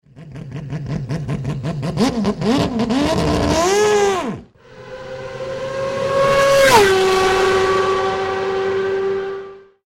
787B_Sound.mp3